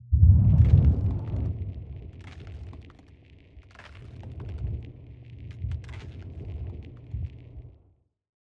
Burn long.wav